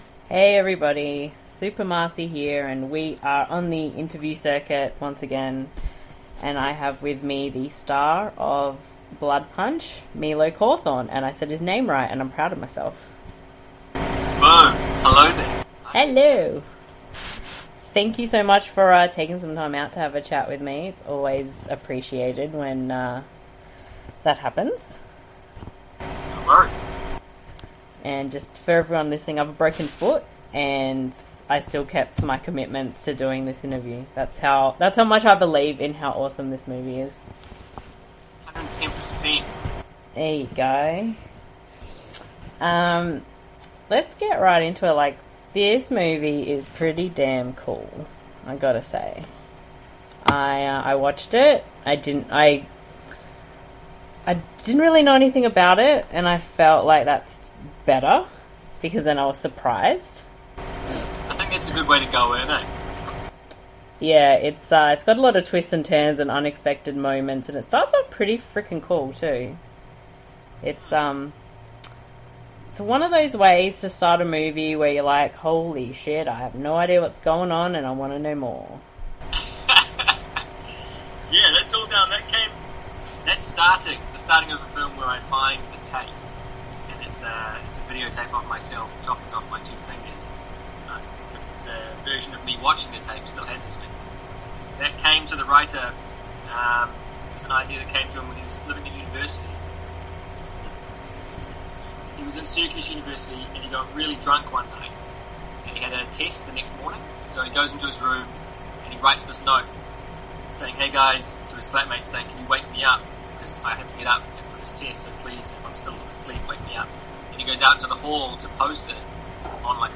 Before you listen to the interview there were a few audio issues, I have tired to correct them as best as possible.
What is better than listening to an interview with an Aussie and a Kiwi?